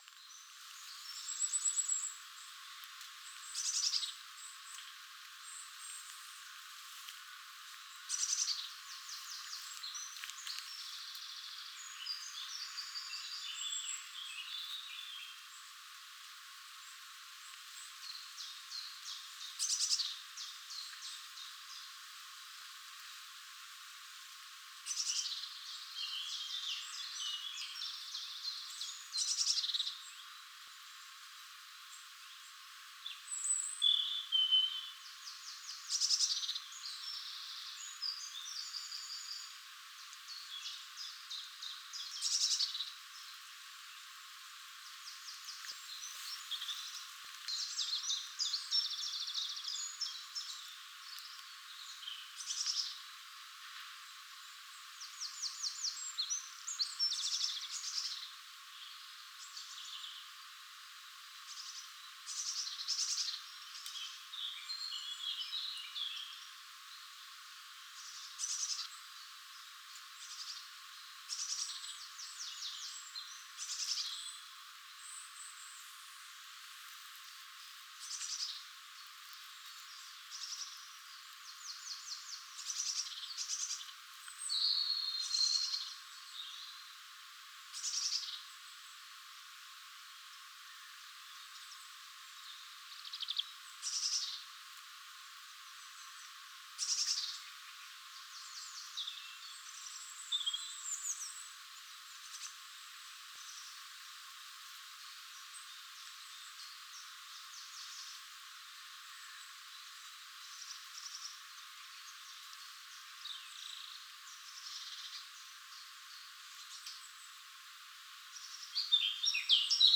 Inspired by University of Surrey research into wellbeing and natural soundscapes, enjoy audio from a woodland walk at Surrey Research Park.
SRP_Birds_3Mins.wav